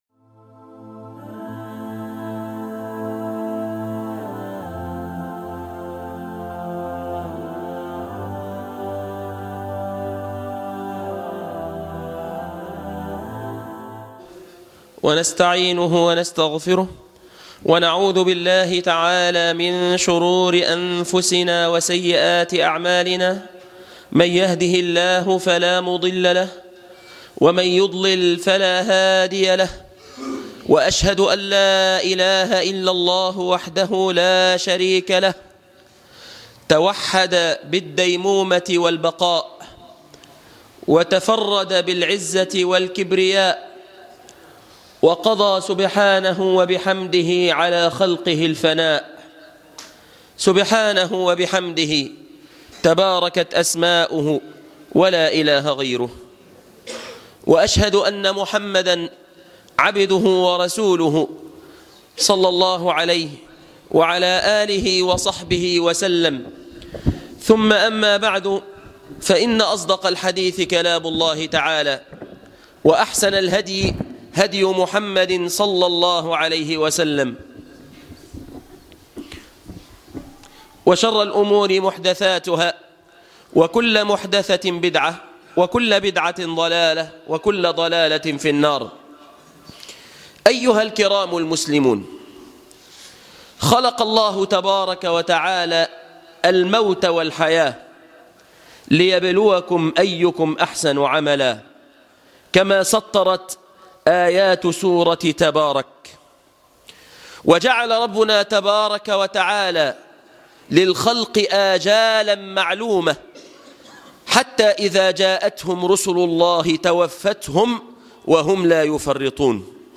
المشهد الاخير - خطبة الجمعة